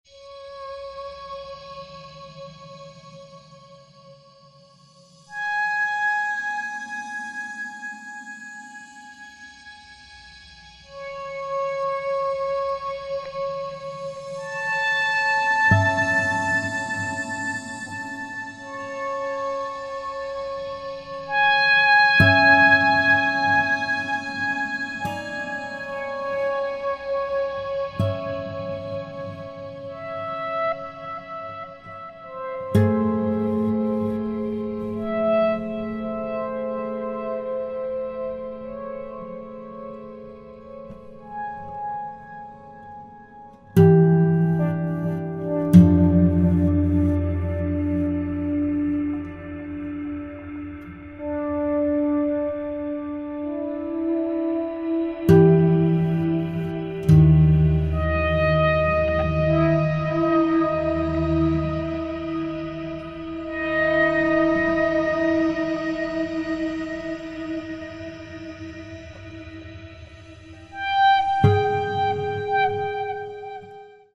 Electric guitar, Samples